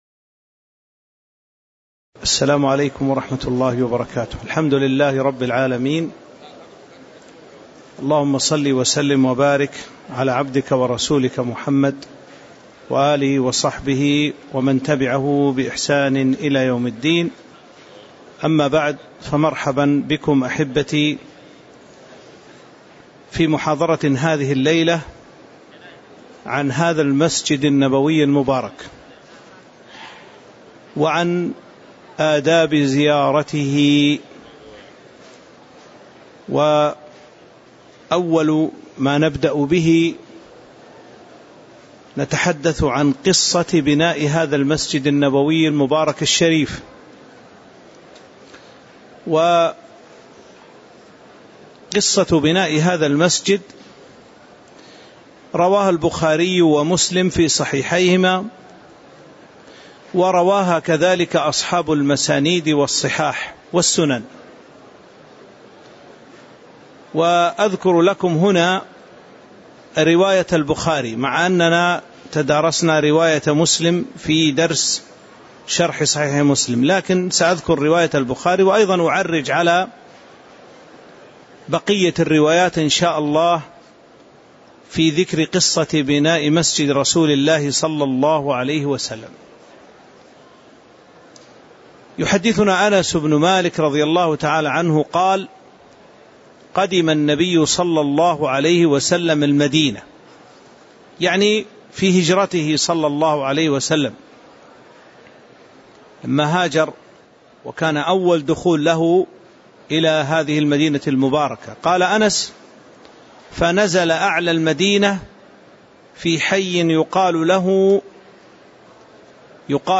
تاريخ النشر ٢٠ رجب ١٤٤٥ هـ المكان: المسجد النبوي الشيخ